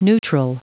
Prononciation du mot neutral en anglais (fichier audio)
Prononciation du mot : neutral
neutral.wav